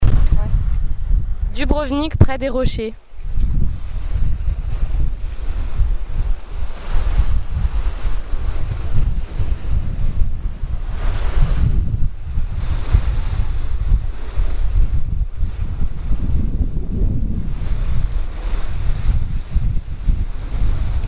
Dubrovnik et la mer adriatique nous parlent. (à proximité du café Buza)